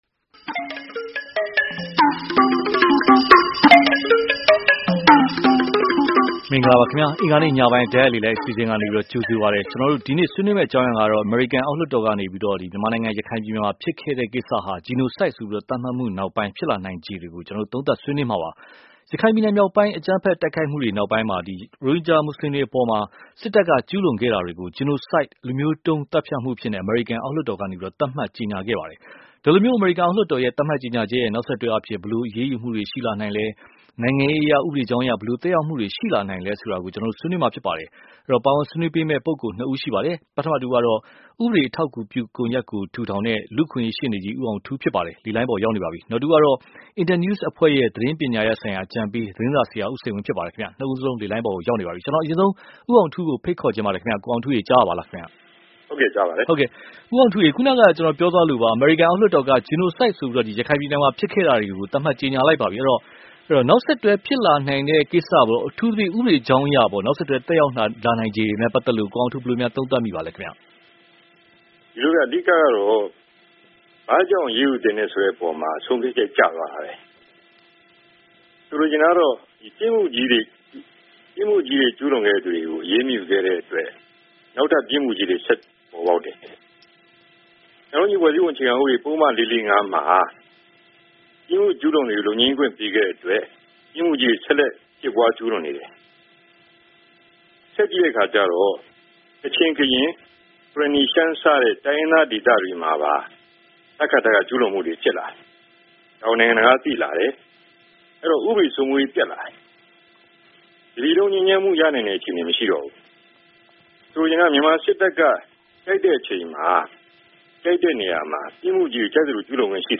Genocide သတ်မှတ်မှုနောက် သက်ရောက်မှု (တိုက်ရိုက်လေလှိုင်း)